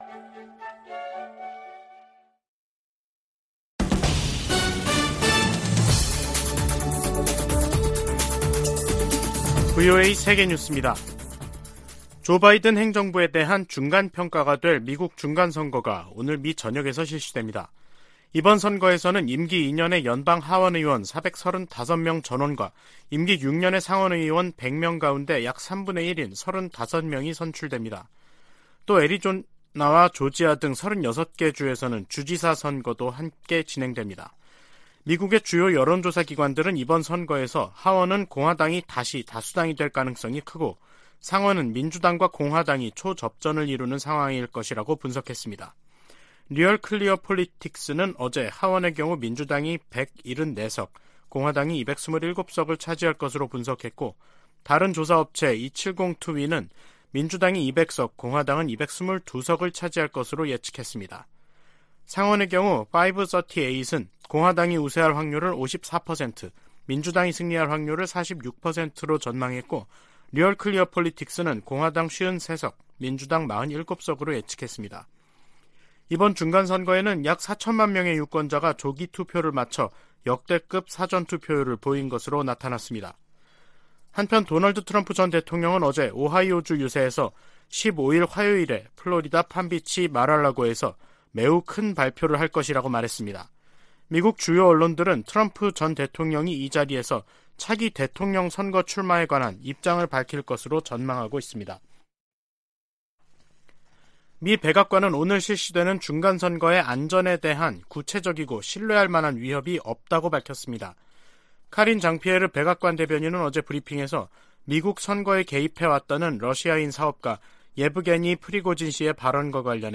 VOA 한국어 간판 뉴스 프로그램 '뉴스 투데이', 2022년 11월 8일 3부 방송입니다. 미국에서 임기 2년의 연방 하원의원 435명 전원과 임기 6년의 연방 상원의원 3분의 1을 선출하는 중간선거 투표가 실시되고 있습니다. 미 국무부는 유엔 안보리에서 북한에 대한 제재와 규탄 성명 채택을 막고 있는 중국과 러시아를 정면으로 비판했습니다. 유럽연합은 북한의 잇단 미사일 도발이 전 세계에 심각한 위협이라며 국제사회의 단합된 대응을 촉구했습니다.